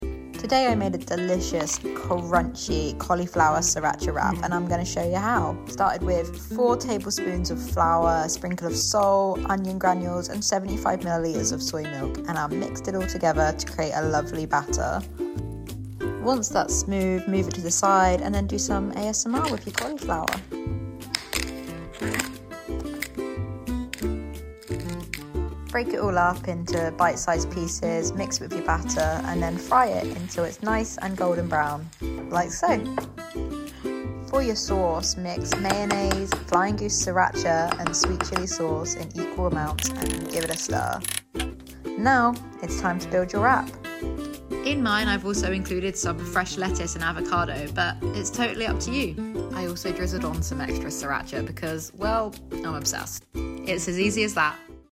Sound on 🔊 for the crunch and sizzle